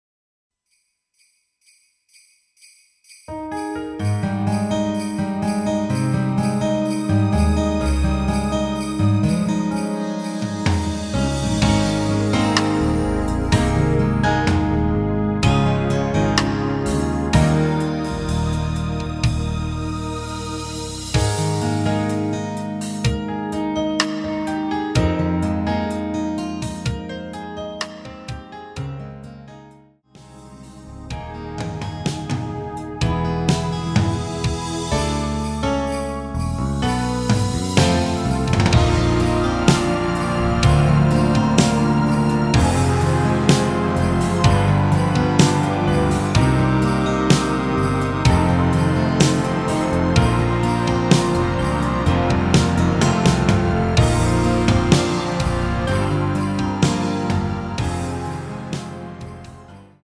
엔딩이 페이드아웃이라 엔딩을 만들어 놓았습니다.
Ab
앞부분30초, 뒷부분30초씩 편집해서 올려 드리고 있습니다.
중간에 음이 끈어지고 다시 나오는 이유는